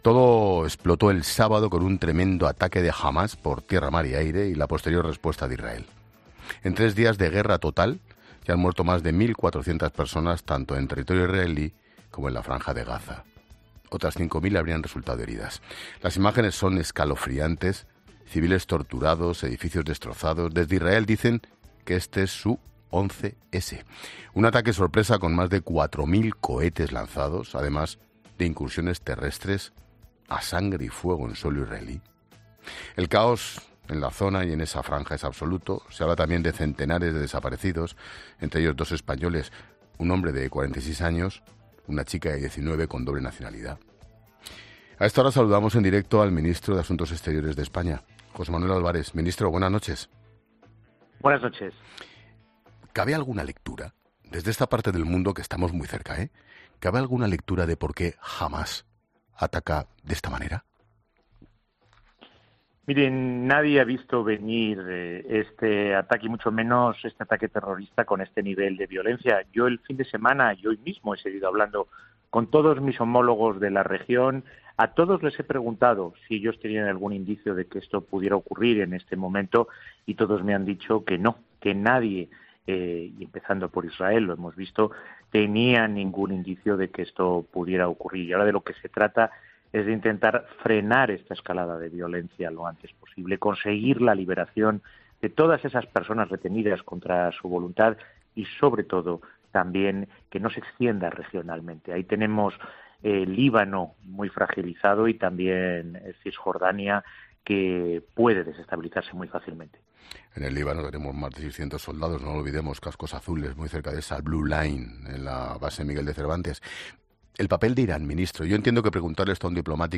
Carlos Herrera, director y presentador de 'Herrera en COPE', comienza el programa de este martes analizando las principales claves de la jornada que pasan, entre otras cosas, por las palabras de miembros de Sumar y Yolanda Díaz sobre el conflicto entre Israel y Hamás.